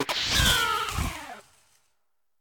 Cri de Miascarade dans Pokémon Écarlate et Violet.